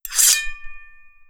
Sword4.wav